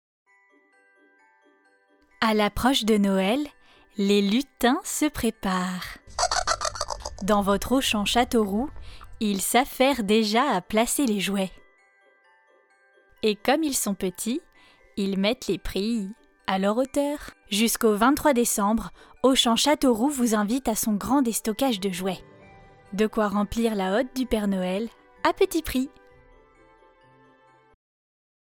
Démo voix Publicité
Voix off